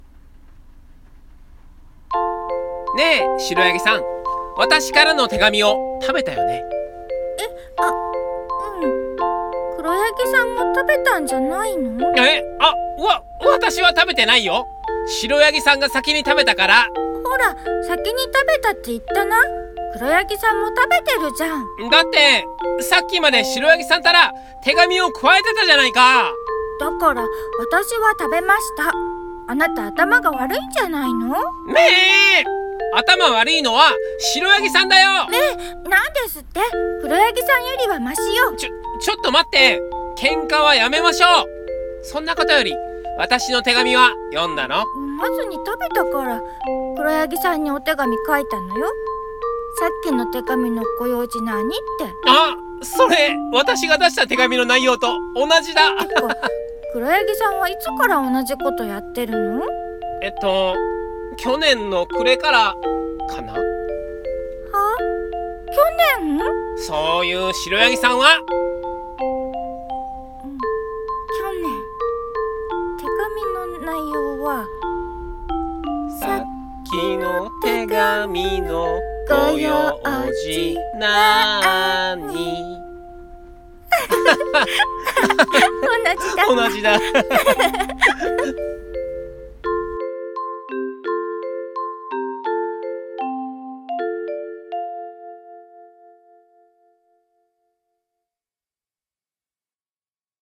【二人用声劇台本】白ヤギさんと黒ヤギさん